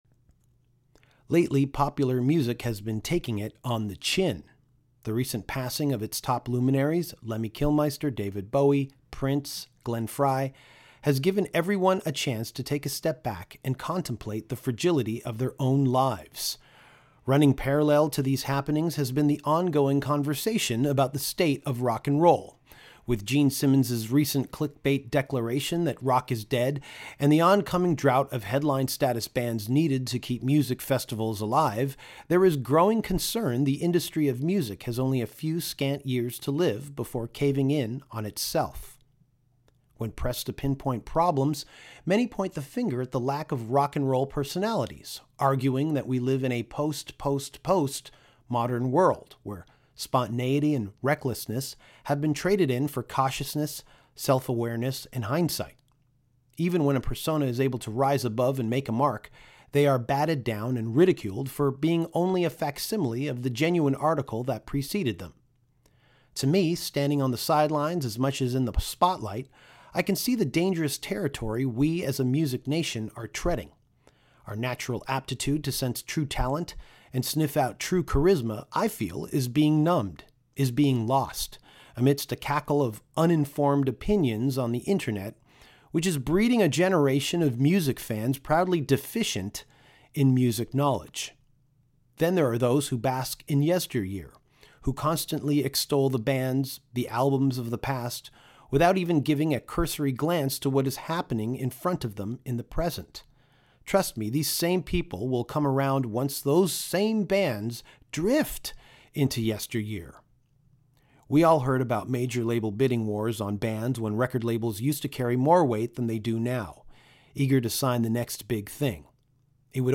Abbath sat down with Danko, while in Toronto on his North American tour, to talk about Kiss and Motorhead…and even Van Halen.